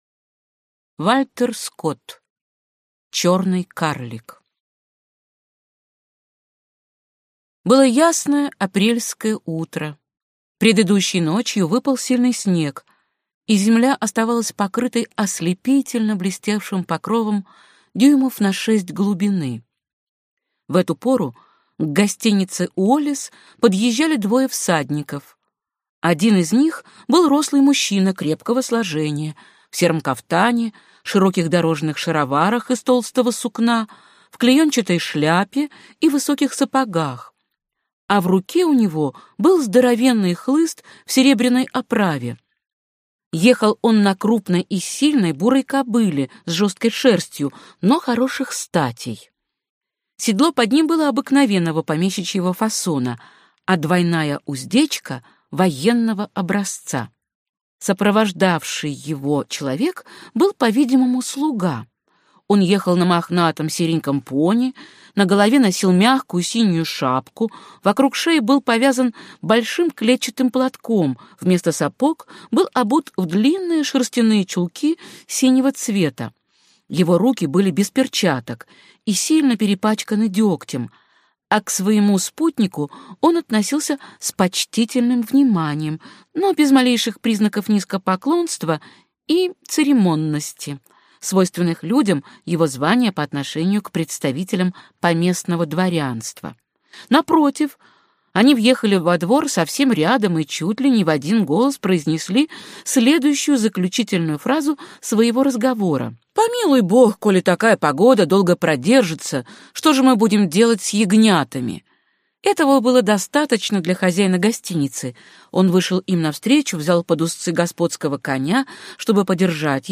Аудиокнига Черный Карлик | Библиотека аудиокниг